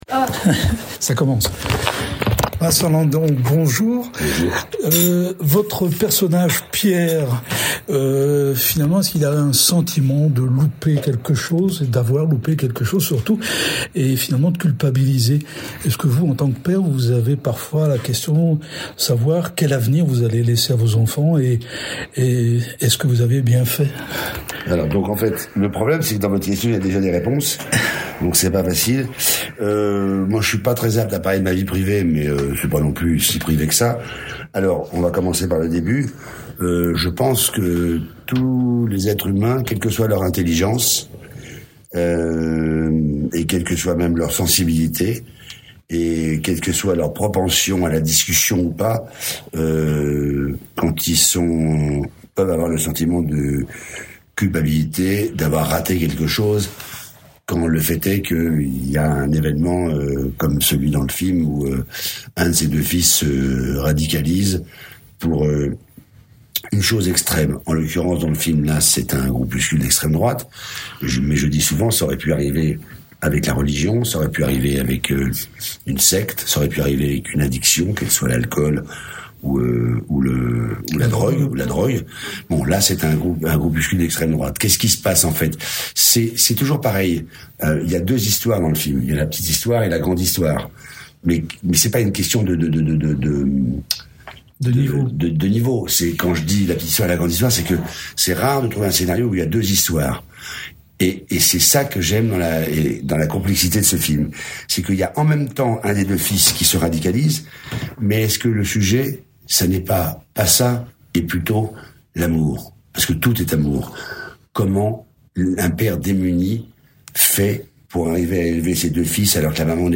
Rencontre avec l’artiste..